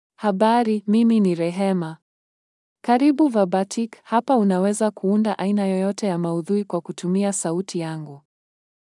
Rehema — Female Swahili AI voice
Rehema is a female AI voice for Swahili (Tanzania).
Voice sample
Listen to Rehema's female Swahili voice.
Rehema delivers clear pronunciation with authentic Tanzania Swahili intonation, making your content sound professionally produced.